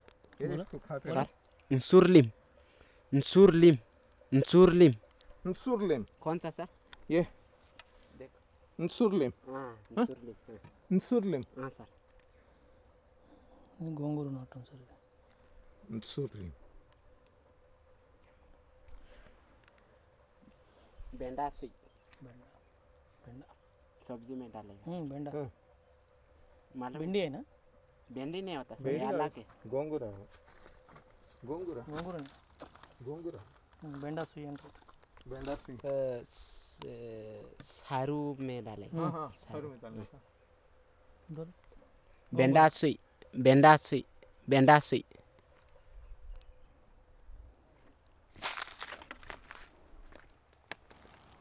Elicitation of words on vegetables and fruits